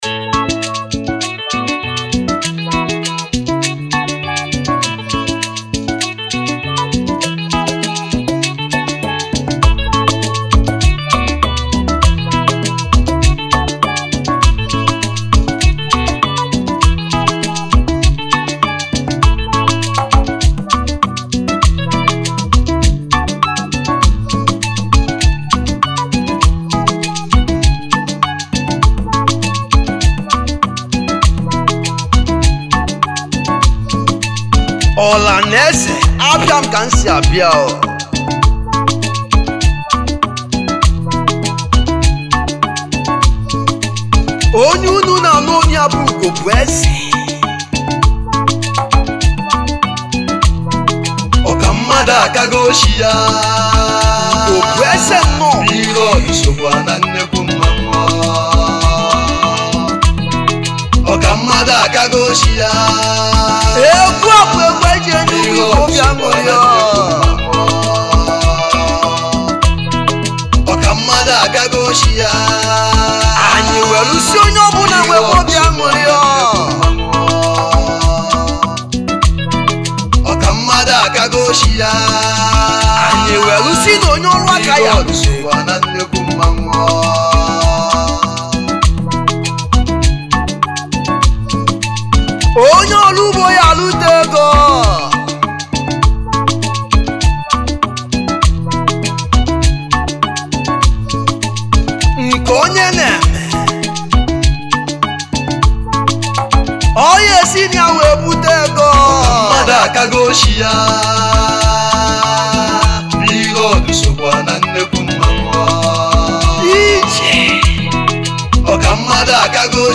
Highlife Traditional Free